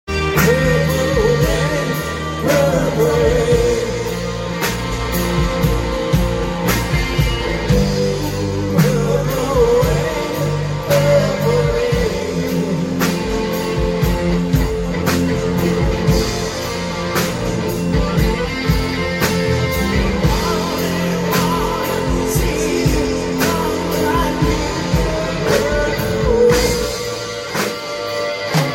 Live at the plate River Grill MHK Sound